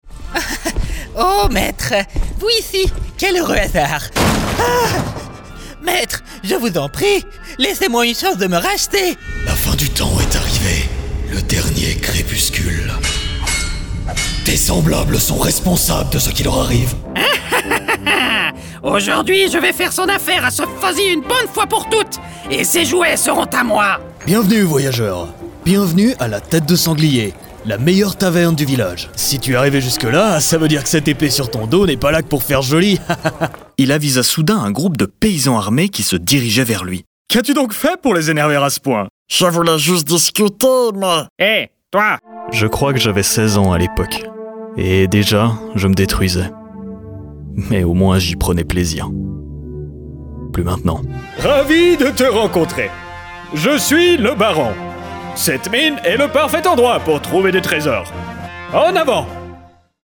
Natural, Versatile, Reliable, Friendly, Corporate
His natural mid-low voice sounds professional and trustworthy but also warm and friendly - perfect for corporate and educational content, while his versatility allows him to voice a wide range of characters and commercials.